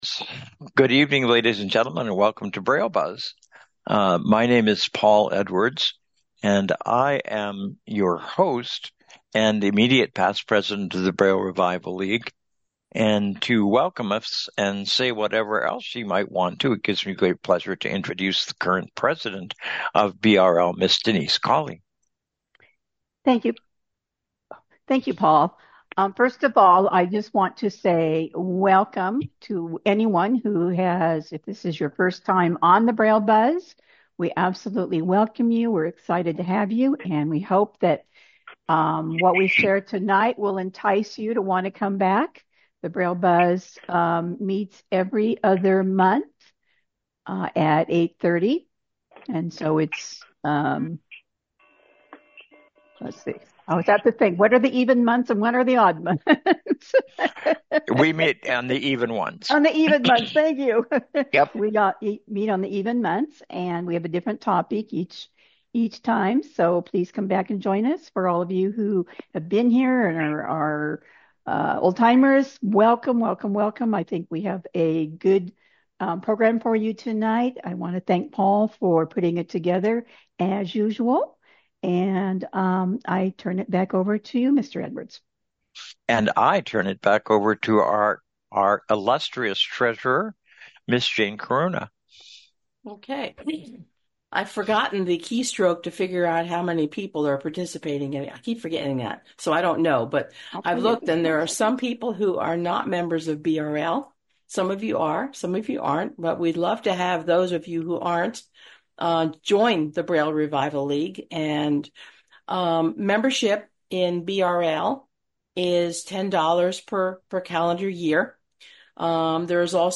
The Braille Buzz is a bimonthly zoom meeting designed to provide information on various aspects of braille. It features guests and discussion surrounding the primary mode of communication for people who are blind.